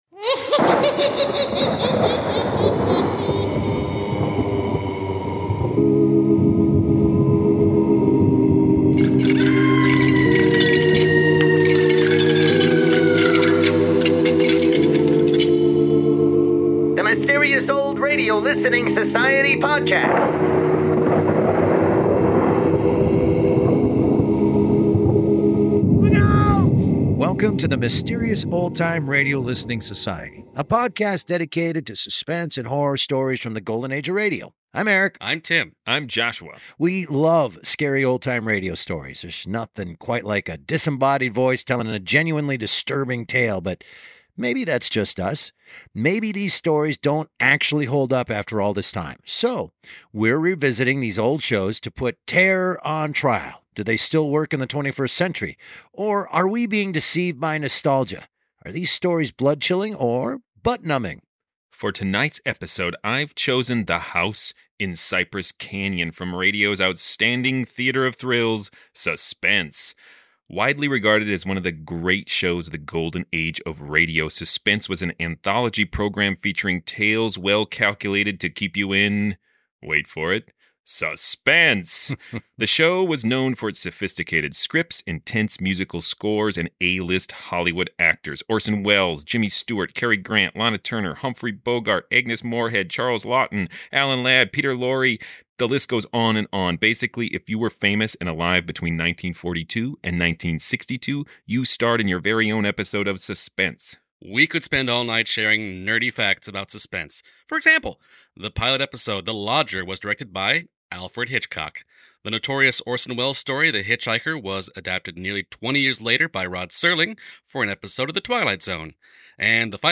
(The original audio from this episode has been replaced with a remastered version of the same content.)